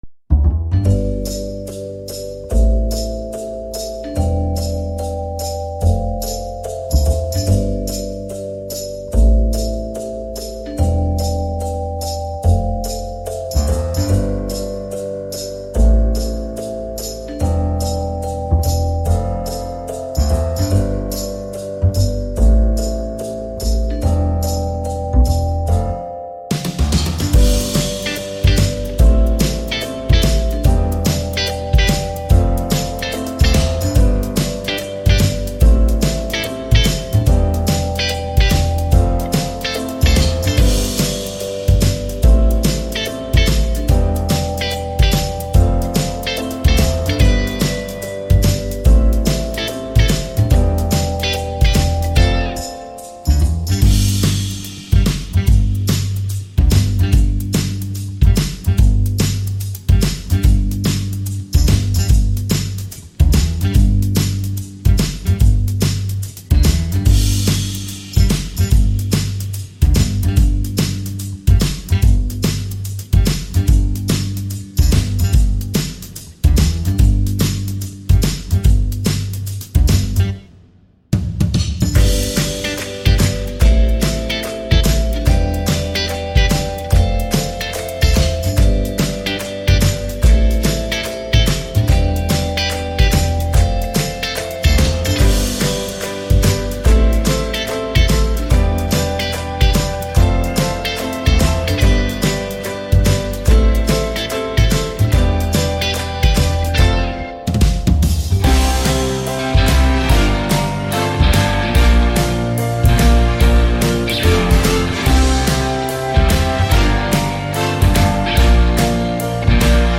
Музыка на день рождения веселая